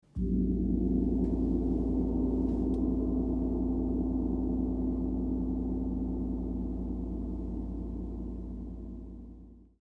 Flash gong (Premium Quality)
Puissant, une richesse d’harmoniques hors du commun, ce Gong chinois est destiné aux personnes en recherche de résonances variées, pour massages sonores ou méditation.
flash-gong-1.mp3